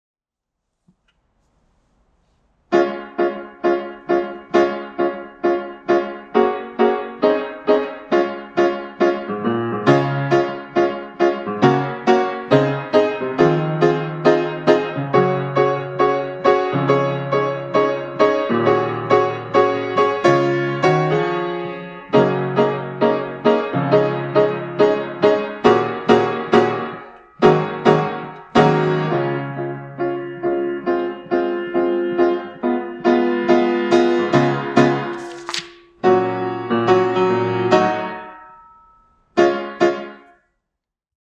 Accompaniment only (sing to this for taping)